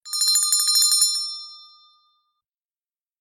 جلوه های صوتی
دانلودصدای زنگوله 2 از ساعد نیوز با لینک مستقیم و کیفیت بالا